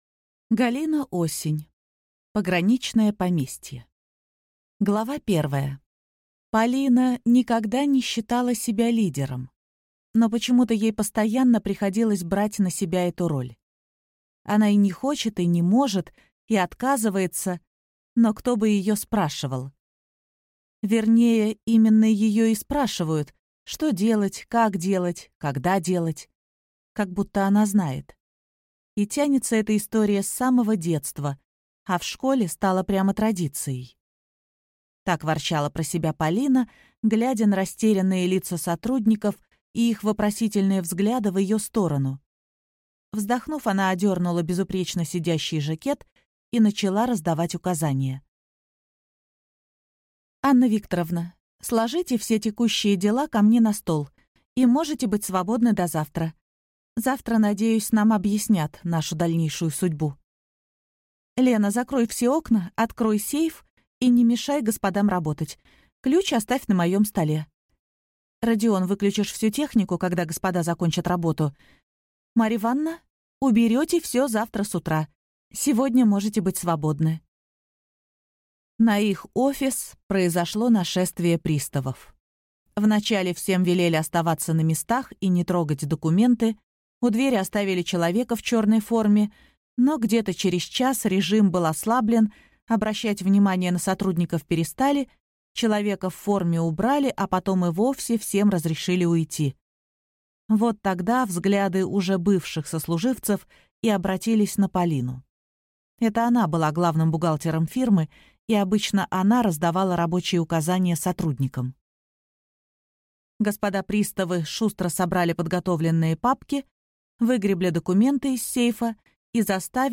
Аудиокнига Пограничное поместье | Библиотека аудиокниг
Прослушать и бесплатно скачать фрагмент аудиокниги